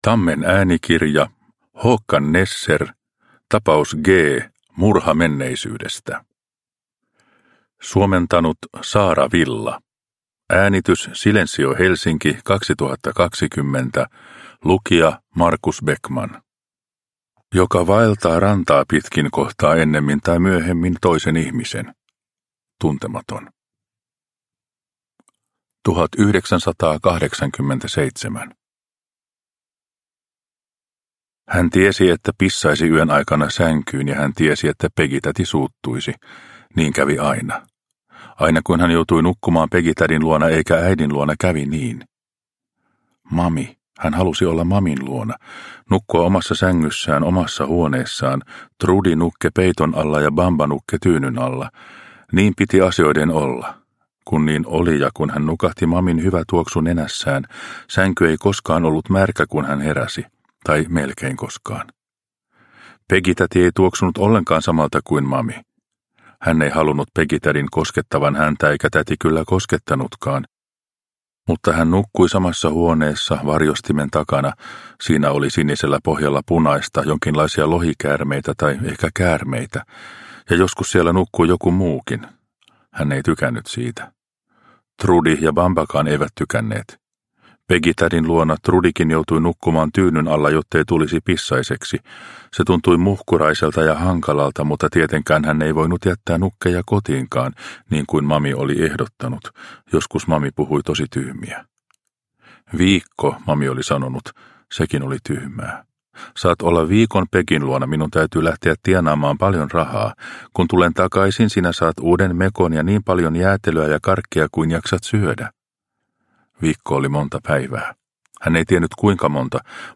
Tapaus G murha menneisyydestä – Ljudbok – Laddas ner